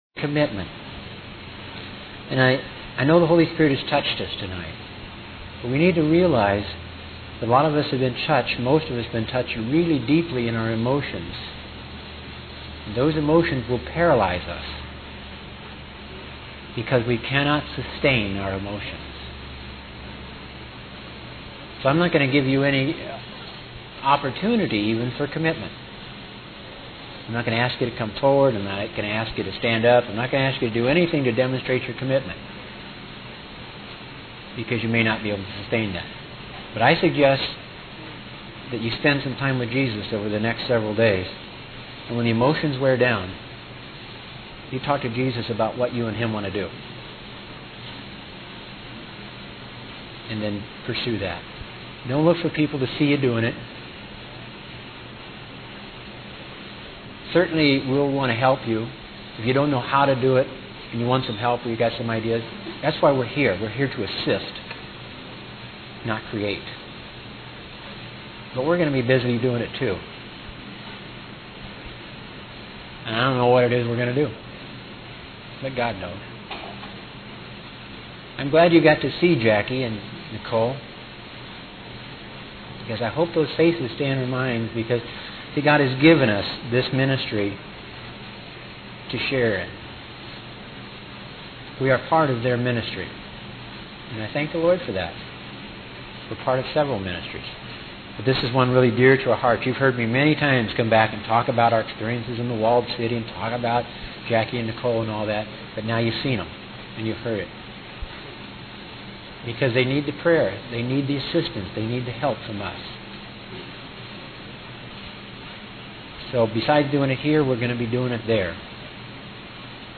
In this sermon, the speaker emphasizes the importance of giving to those in need.